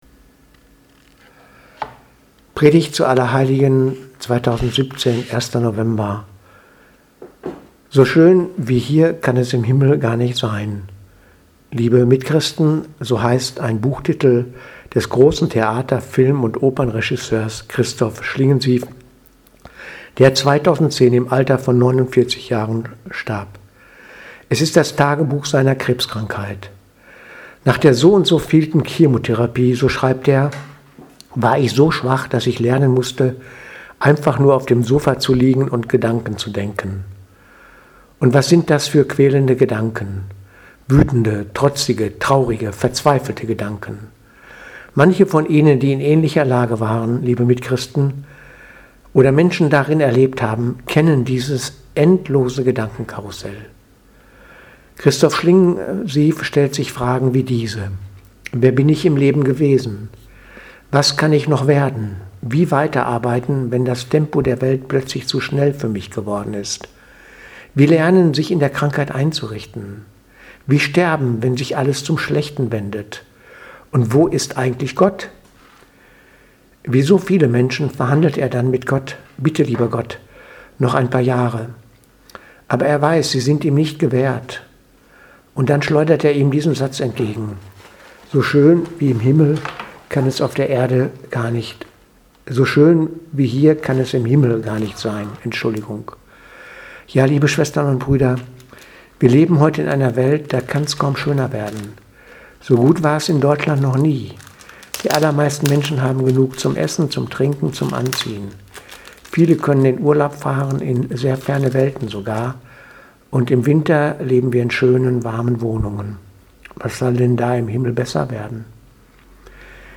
Predigt vom 1.11.2017 – Allerheiligen